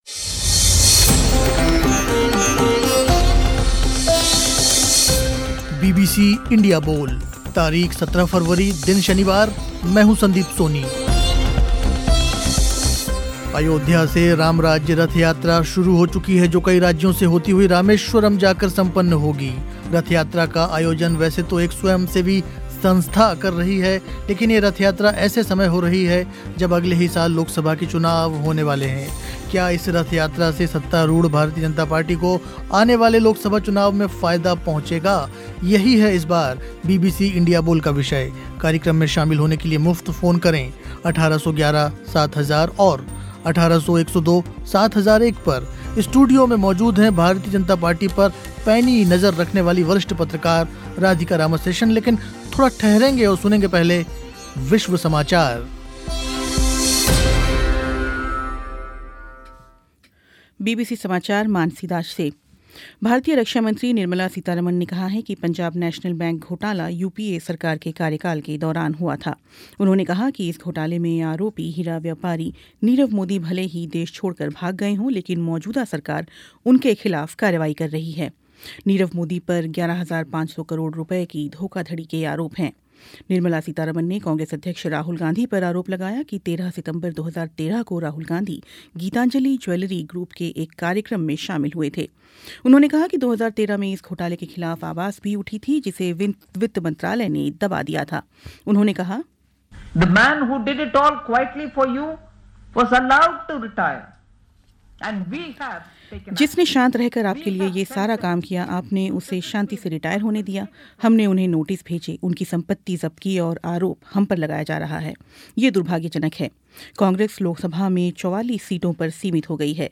स्टूडियो में मौजूद थीं वरिष्ठ पत्रकार